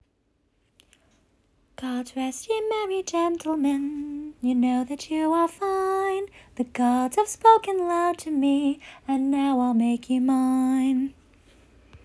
Make you mine (nonsense song, off the top of my head